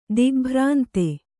♪ digbhrānte